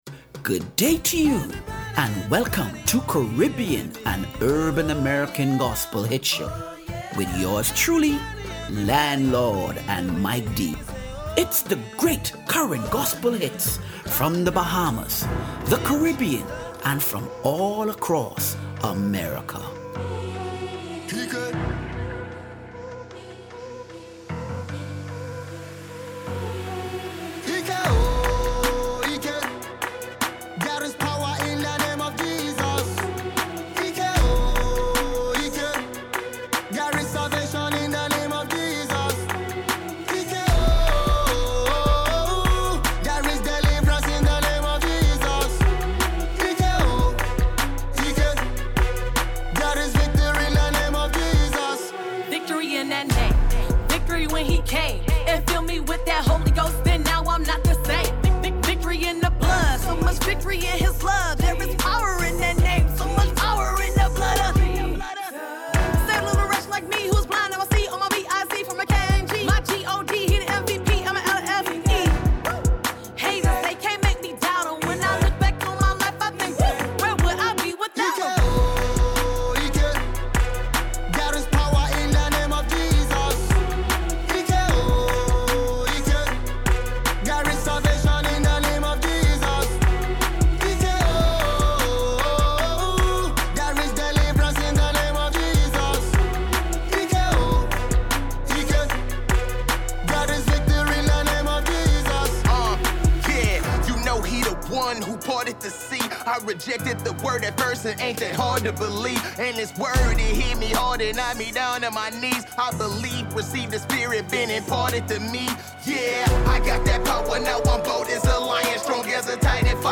Caribbean and Urban American Gospel Hits - April 14 2024